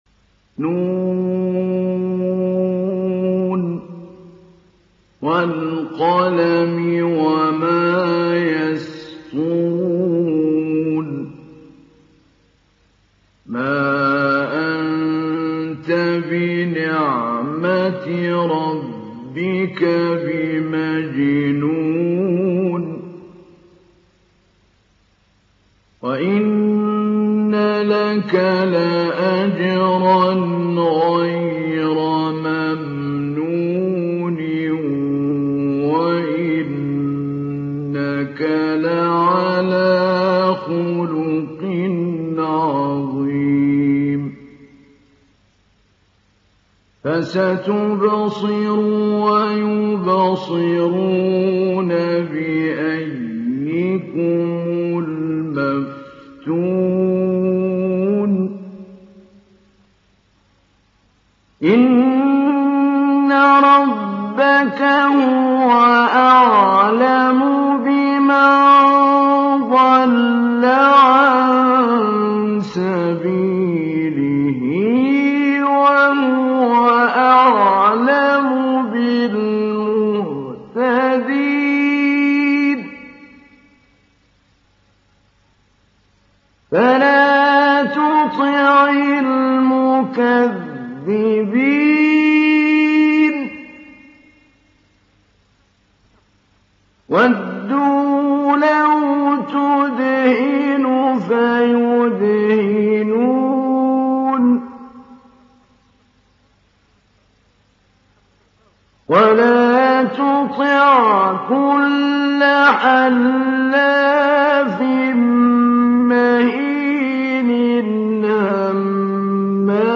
Kalem Suresi İndir mp3 Mahmoud Ali Albanna Mujawwad Riwayat Hafs an Asim, Kurani indirin ve mp3 tam doğrudan bağlantılar dinle
İndir Kalem Suresi Mahmoud Ali Albanna Mujawwad